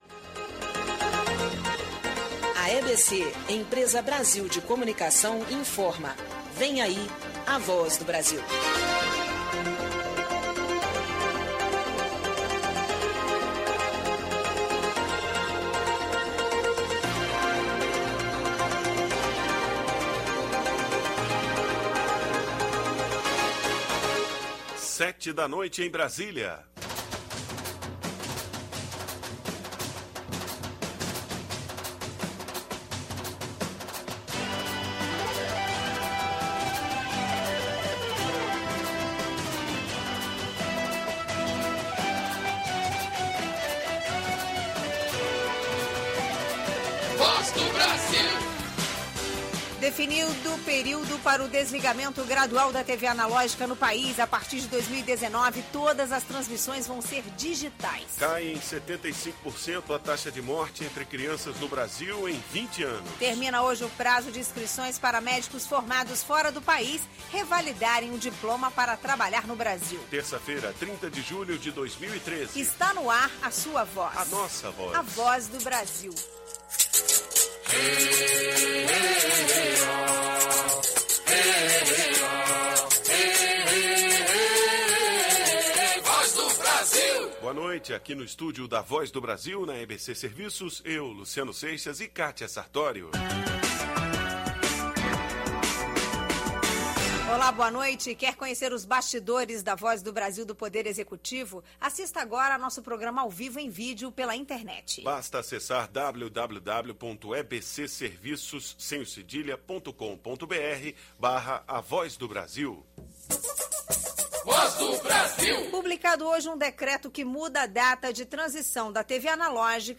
Helicoverpa é tema da Voz do Brasil Ouça a reportagem sobre a realização do Seminário Brasileiro sobre Helicoverpa, que reuniu mais de mil produtores, técnicos, pesquisadores e agentes do governo, no último dia 30/07, em Luiz Eduardo Magalhães (BA).